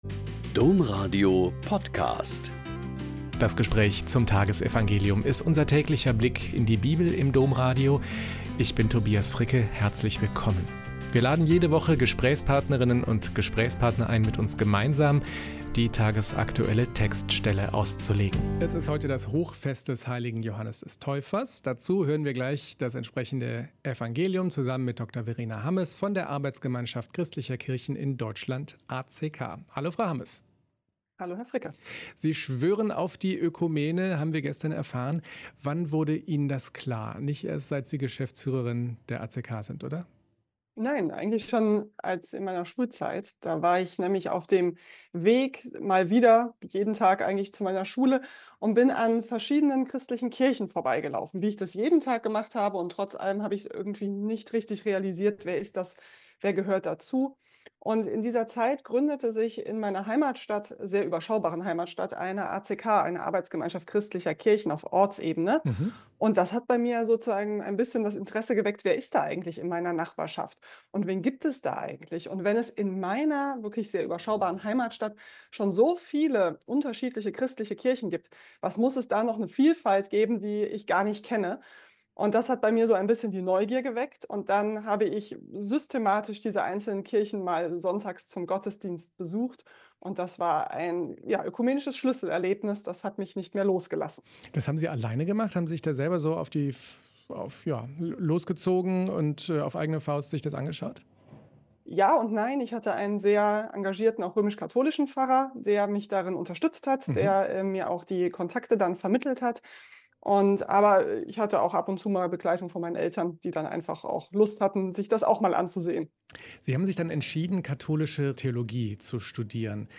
Gespärch